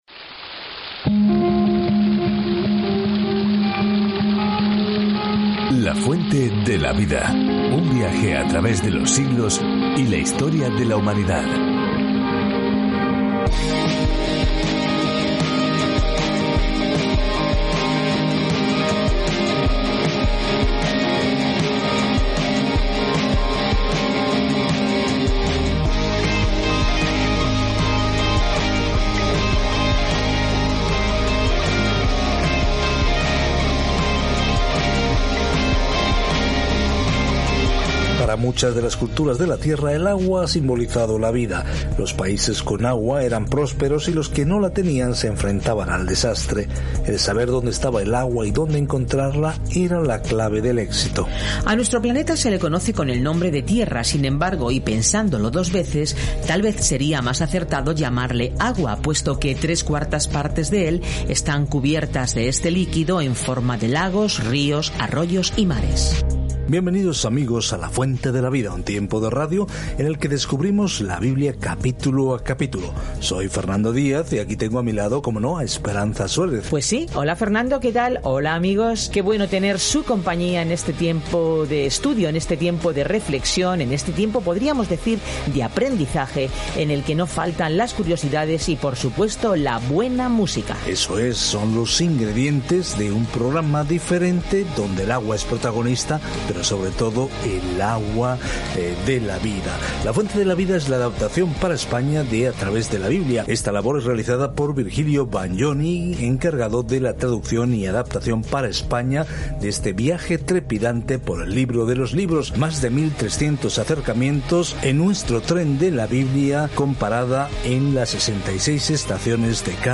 Scripture John 19:17-42 John 20:1-2 Day 38 Start this Plan Day 40 About this Plan Las buenas noticias que Juan explica son únicas de los otros evangelios y se centran en por qué debemos creer en Jesucristo y cómo tener vida en este nombre. Viaja diariamente a través de Juan mientras escuchas el estudio de audio y lees versículos seleccionados de la palabra de Dios.